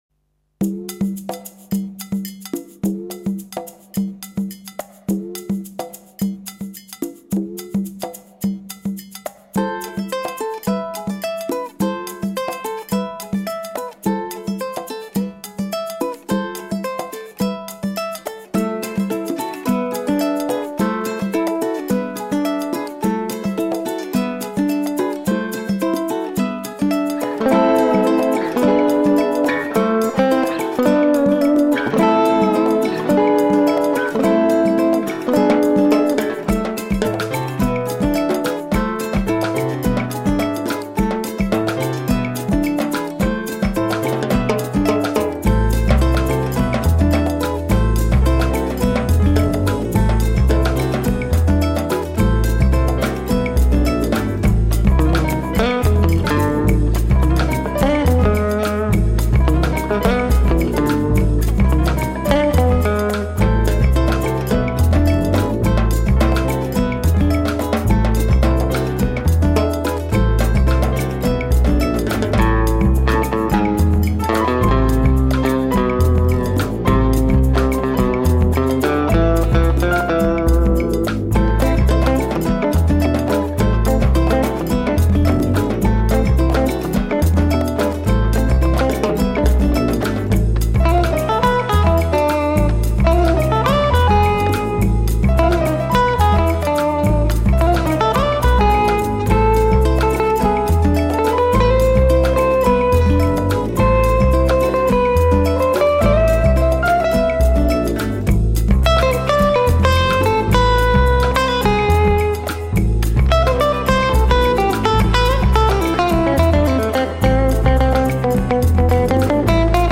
60's twang surf guitar.
real and sampled percussion